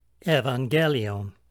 Pronunciation Note: The Upsilon (υ <